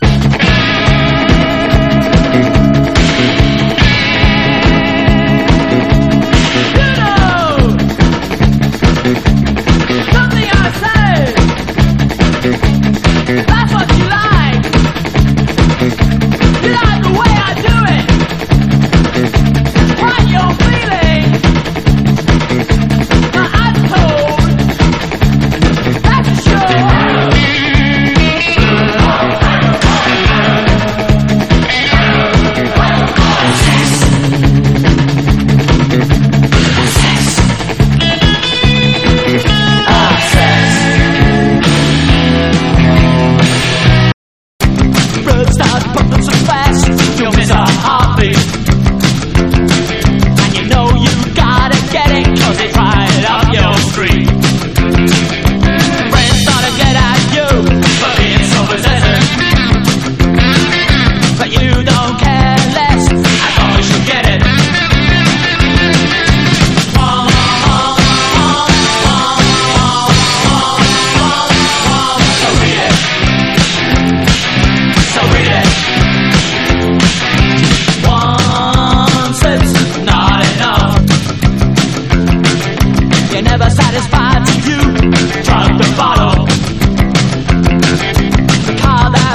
ROCK / PUNK / 70'S/POWER POP/MOD / NEW WAVE / PUB ROCK